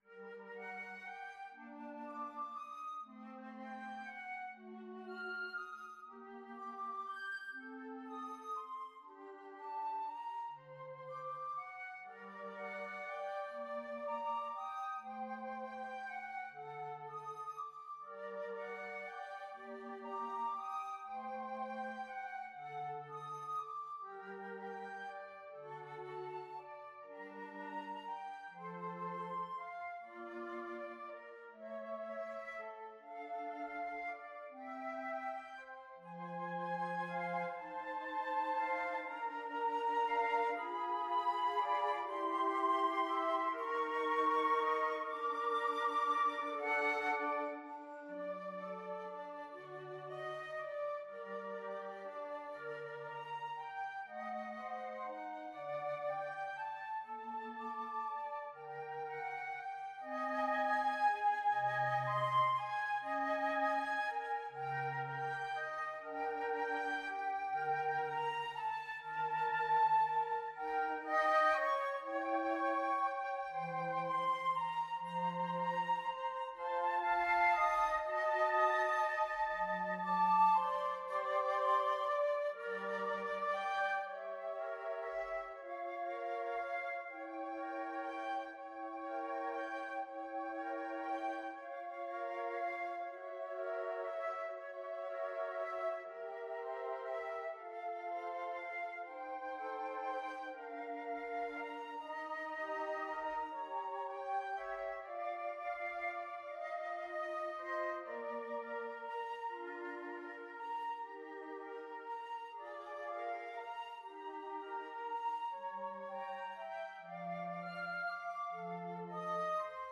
Flute 1Flute 2Flute 3Bass Flute
= 120 Larghetto
12/8 (View more 12/8 Music)
Classical (View more Classical Flute Quartet Music)